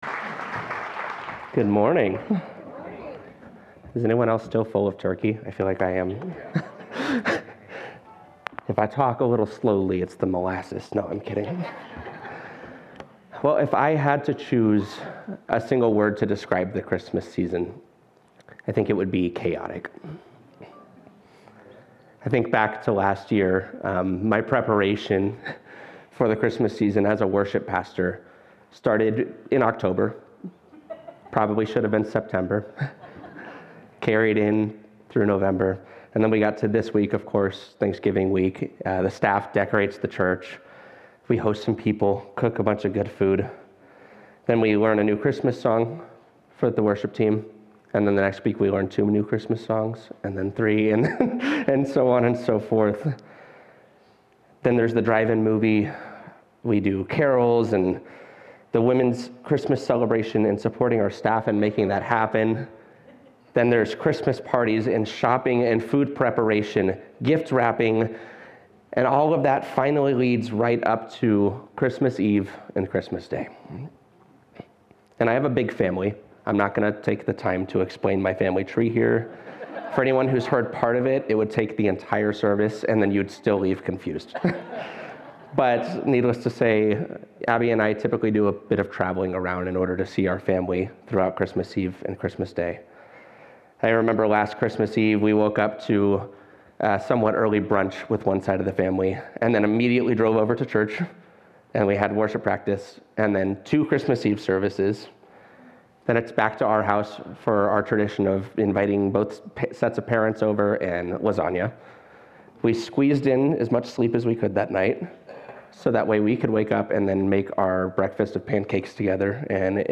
keyboard_arrow_left Sermons / He Shall Be Called Series Download MP3